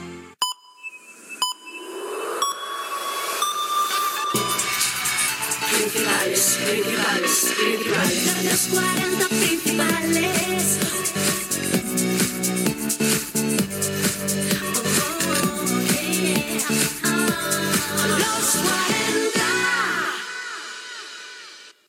Indcatiu horari